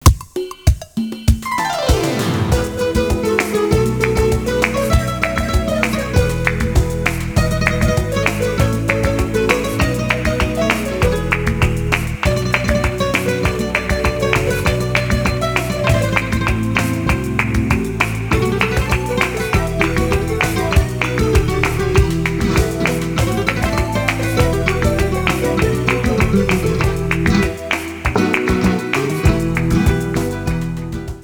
Rumba.
piano
guitarra
melodía